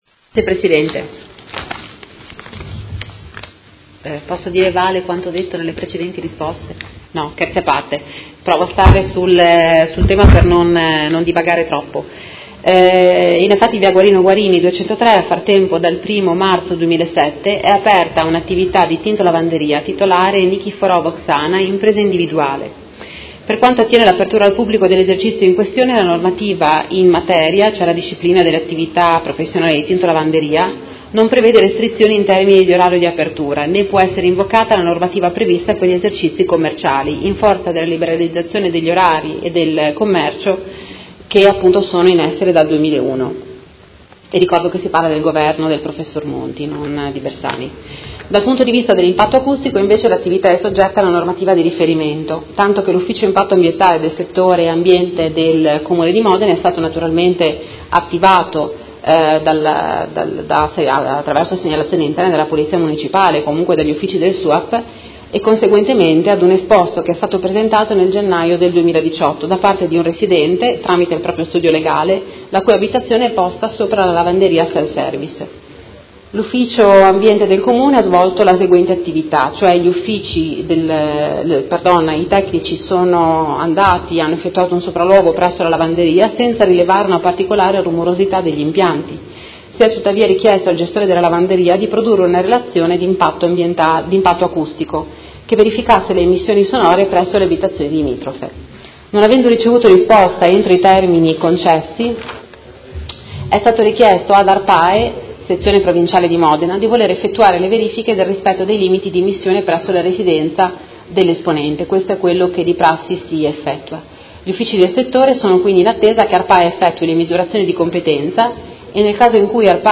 Ludovica Ferrari — Sito Audio Consiglio Comunale
Seduta del 7/03/2019 Risponde. Interrogazione del Consigliere Galli (F.I.) avente per oggetto: Risulta all’Amministrazione che in Via Guarini una lavanderia resti aperta e funzionante tutta la notte in spregio ad orari e riposo dei cittadini?